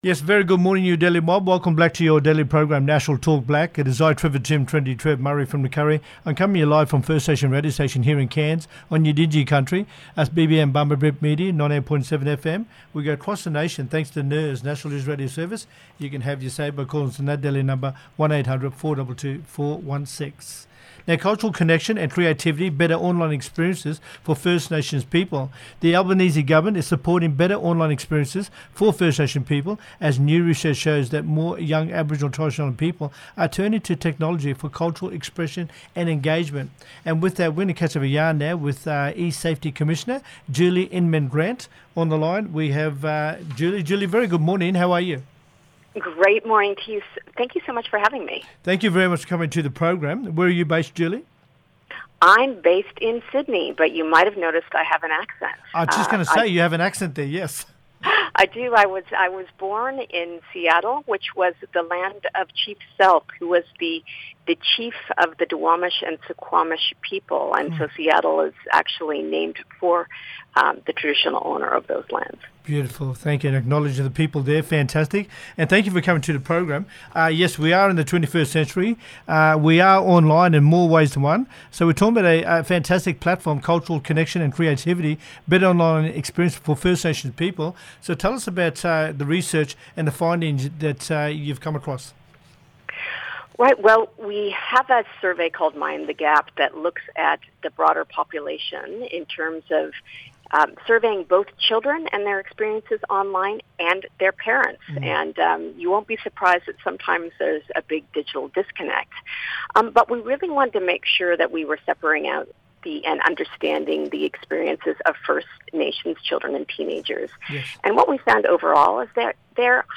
Julie Inman Grant, eSafety Commissioner, talking about better online experiences for First Nations people. The Albanese Government is supporting better online experiences for First Nations people as new research shows that more young Aboriginal and Torres Strait Islander people are turning to technology for cultural expression and engagement.